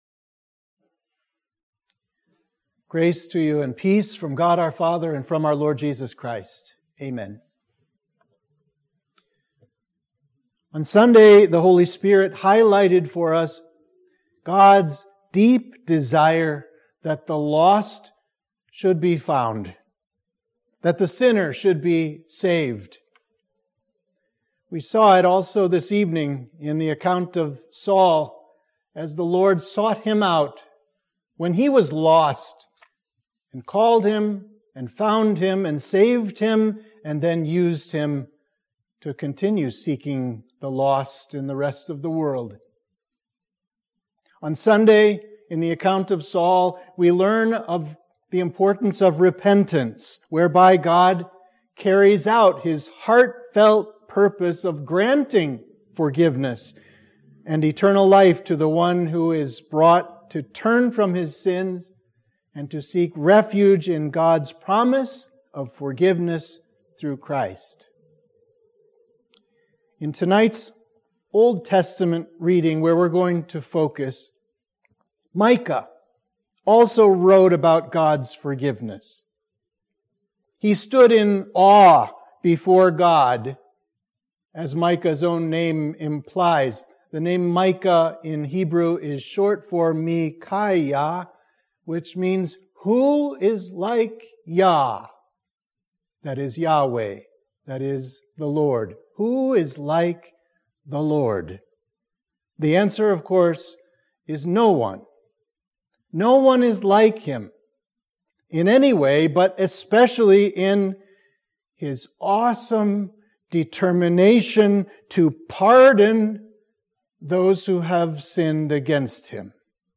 Sermon for Midweek of Trinity 3